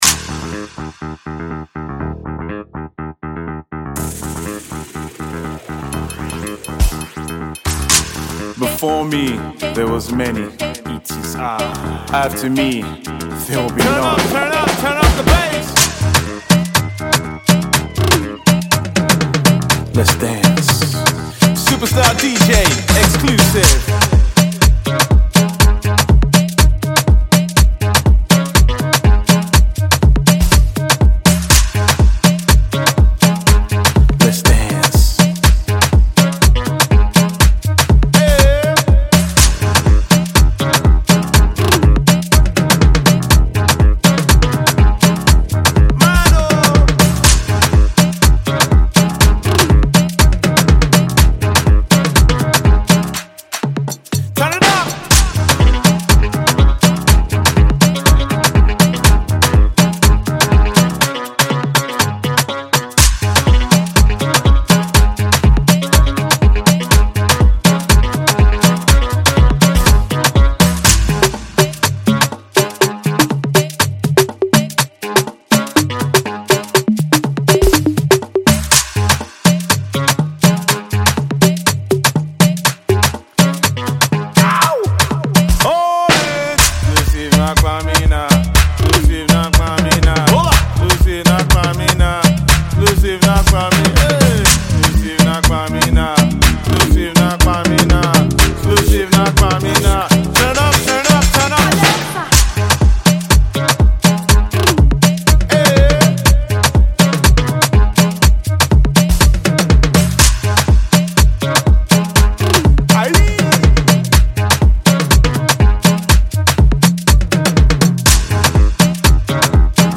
Nigerian disc jockey
banging beat